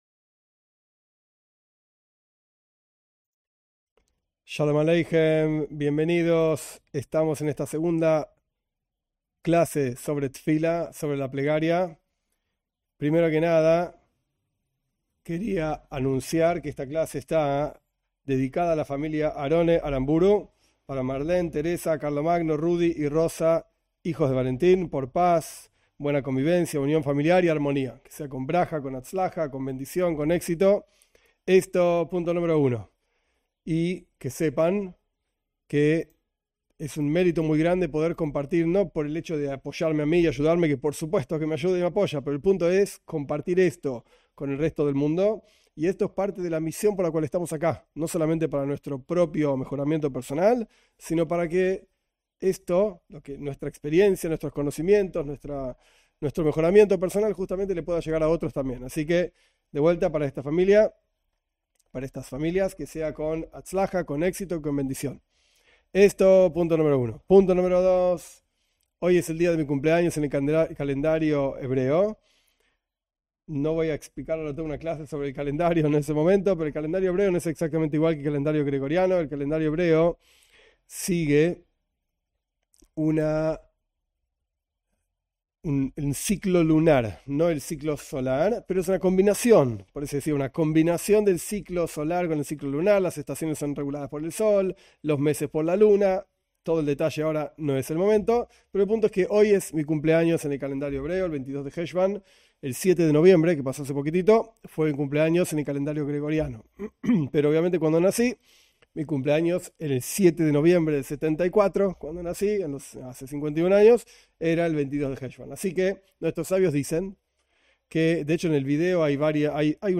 En estas clases se analiza con detenimiento la plegaria para no Judíos según el mensaje del judaísmo.